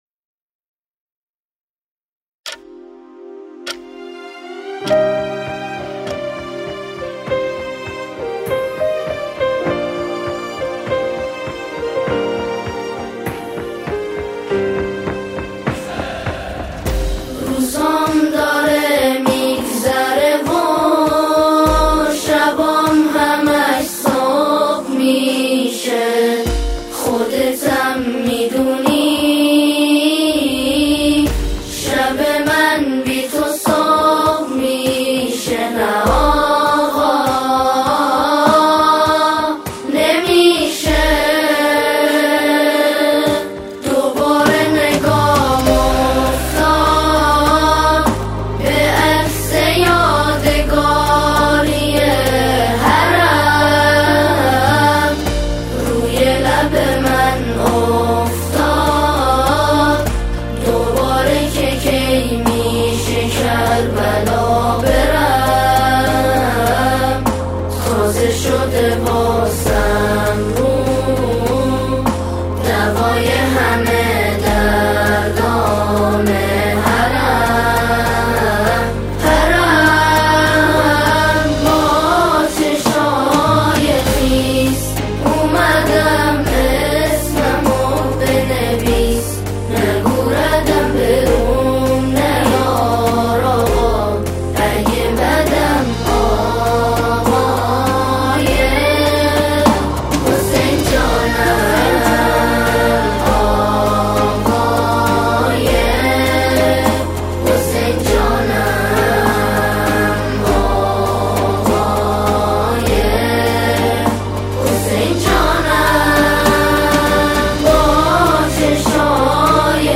شعری را با موضوع اربعین همخوانی می‌کنند.